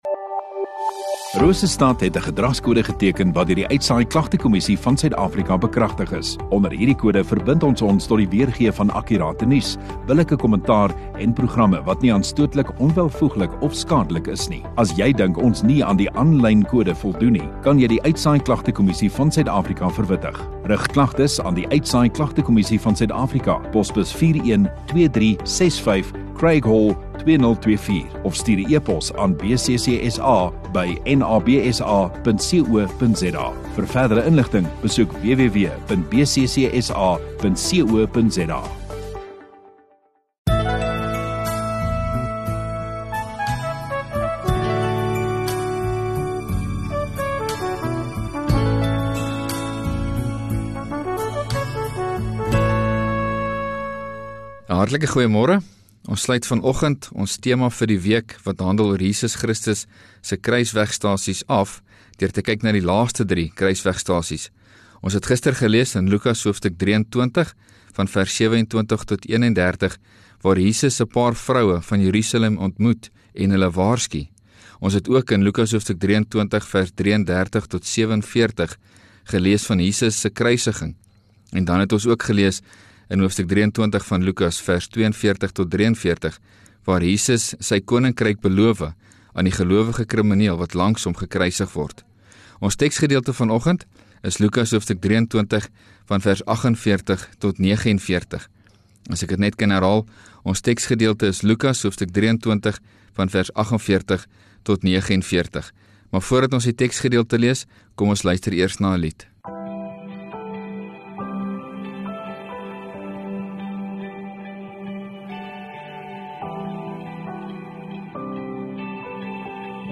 5 Apr Saterdag Oggenddiens